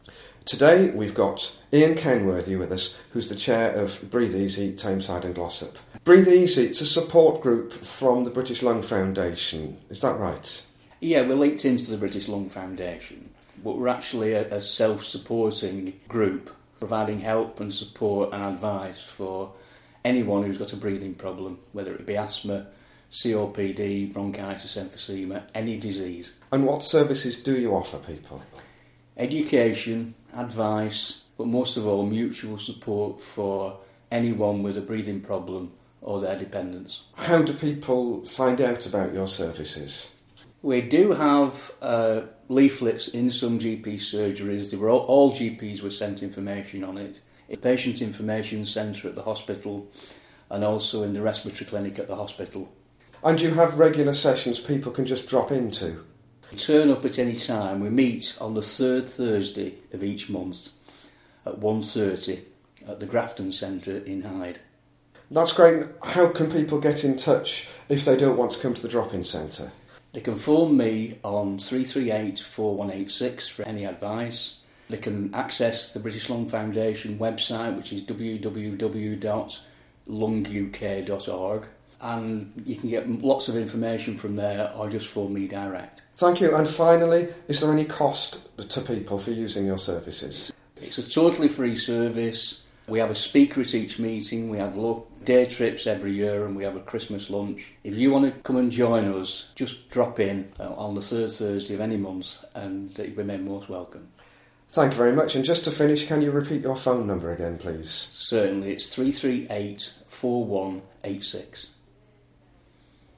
A short interview about the support the Breathe Easy can give to people with COPD in Tameside